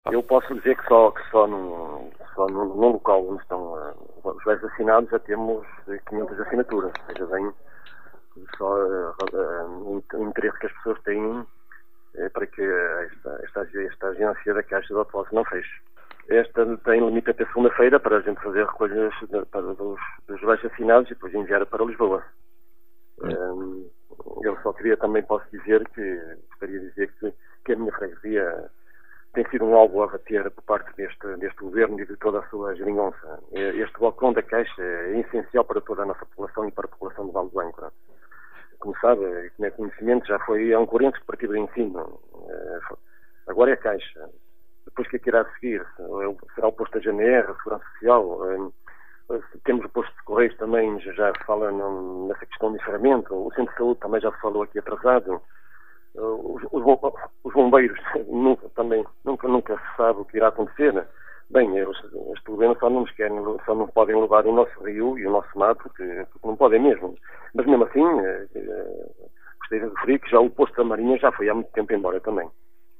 A iniciativa, que decorre até segunda-feira, dia 20 de Março, conta já com mais de 500 assinaturas apenas num dos locais onde estão a ser recolhidas as assinaturas, adiantou à Rádio Caminha o presidente da Junta de Freguesia, Carlos Castro